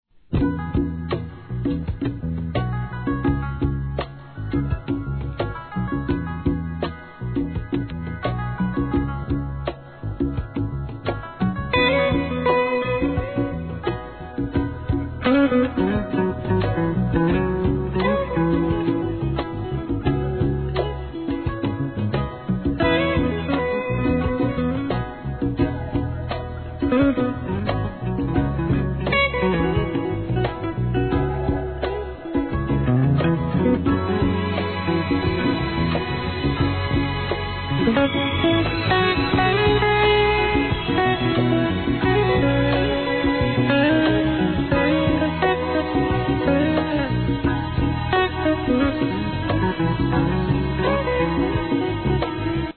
¥ 2,200 税込 関連カテゴリ SOUL/FUNK/etc...